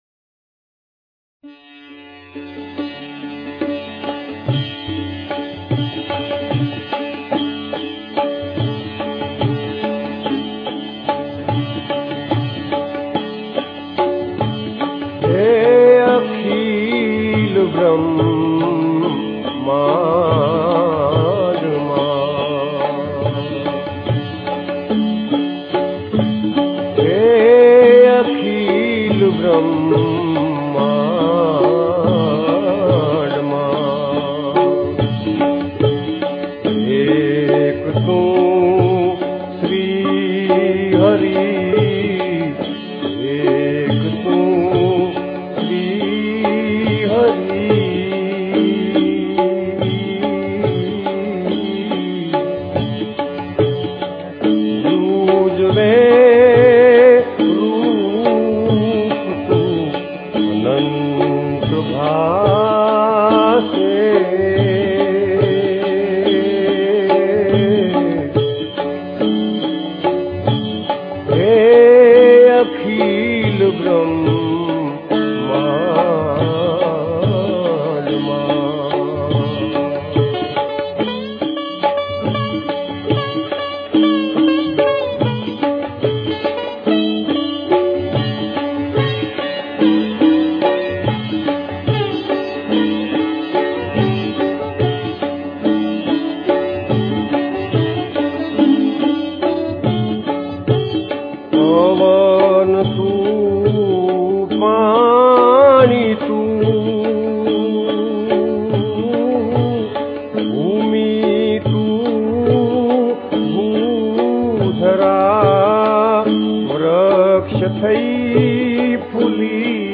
સંતવાણી